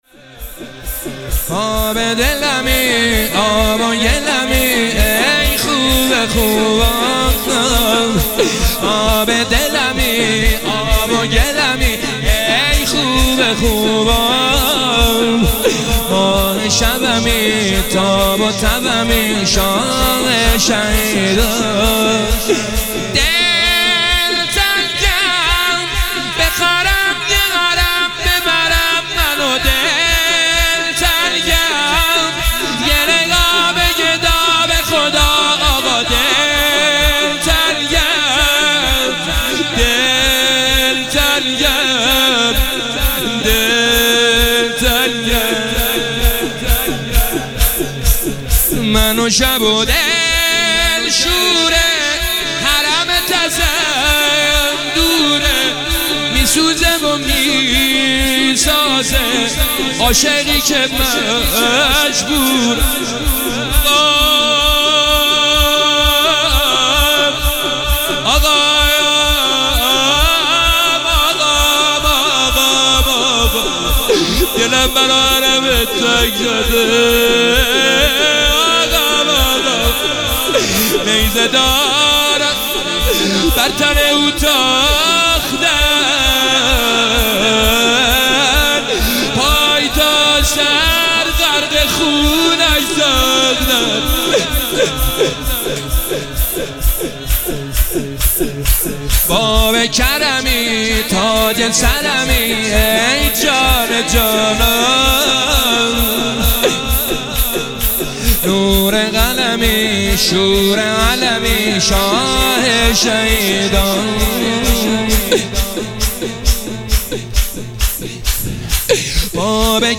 مداحی شور شب 19 ماه رمضان شب قدر 1404